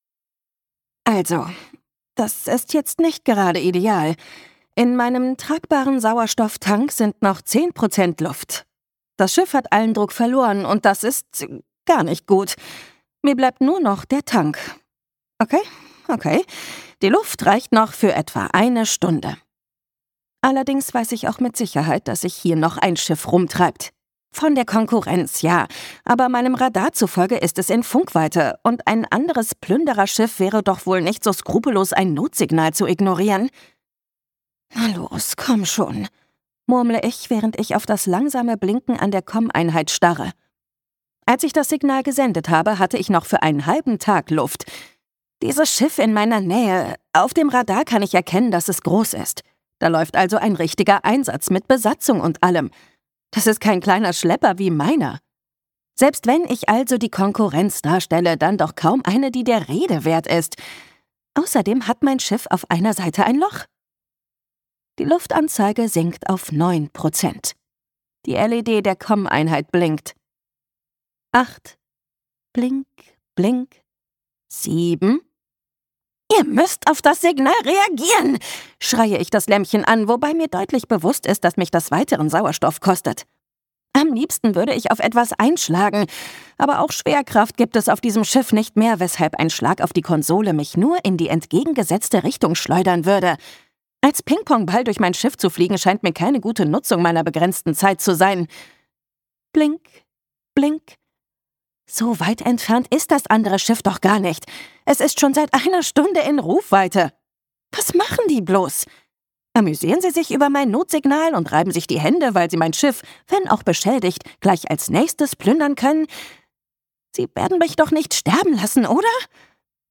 Science-Fiction-Romance von New York Times Bestseller-Autorin Beth Revis
Gekürzt Autorisierte, d.h. von Autor:innen und / oder Verlagen freigegebene, bearbeitete Fassung.